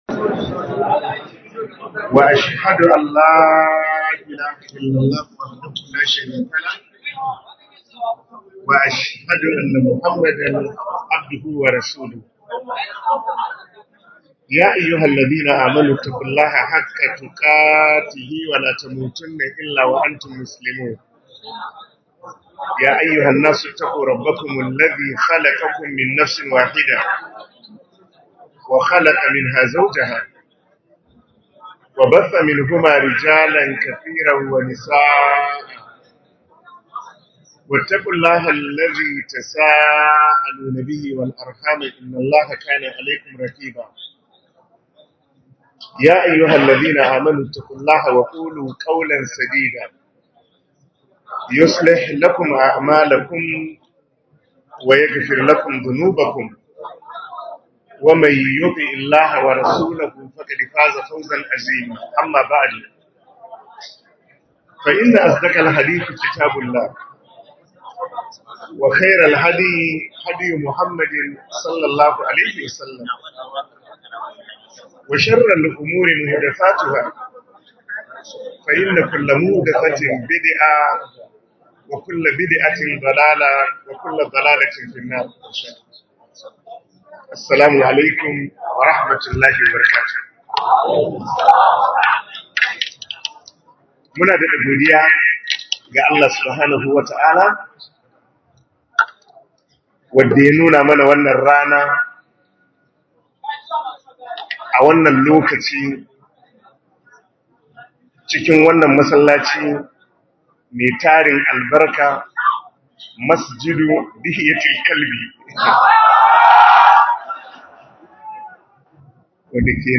Muhadara A Garin Gombe - Rayuwa Babu Lokaci